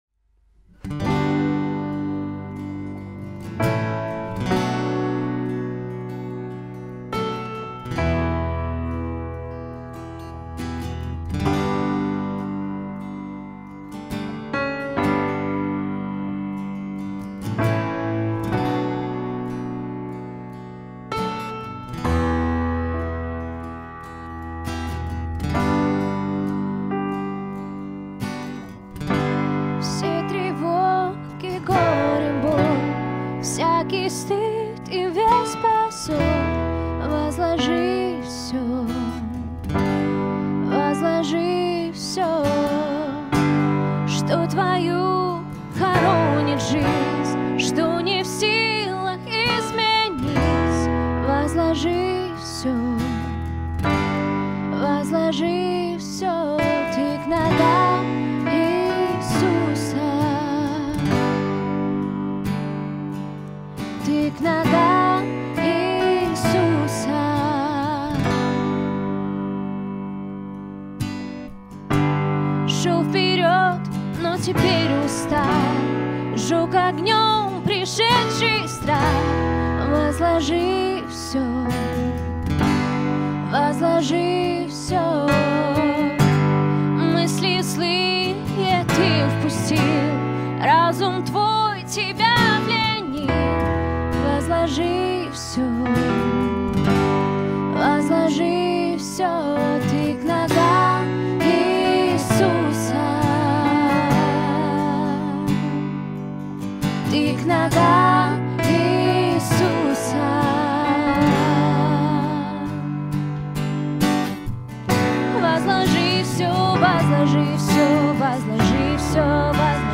895 просмотров 719 прослушиваний 50 скачиваний BPM: 62